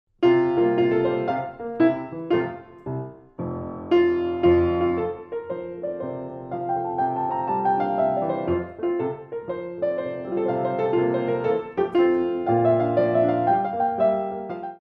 Ballet Music for All Level Classes
Solo Piano
Fast Waltzes